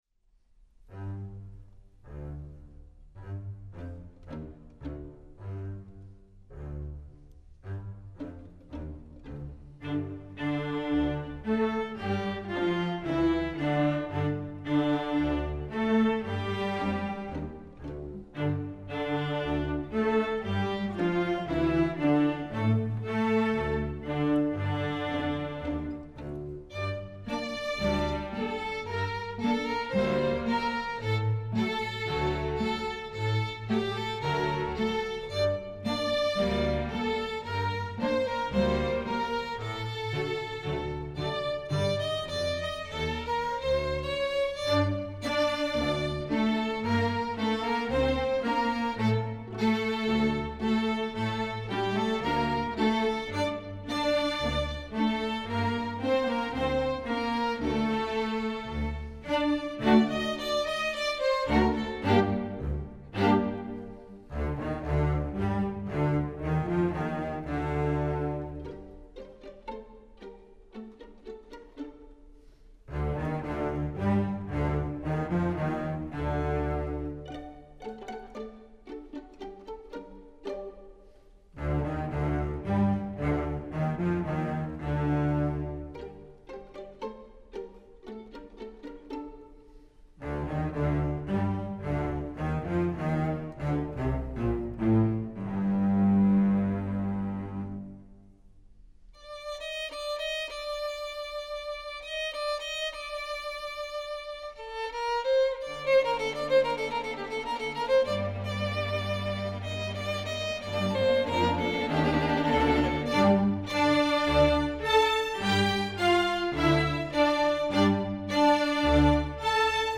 Instrumentation: string orchestra
instructional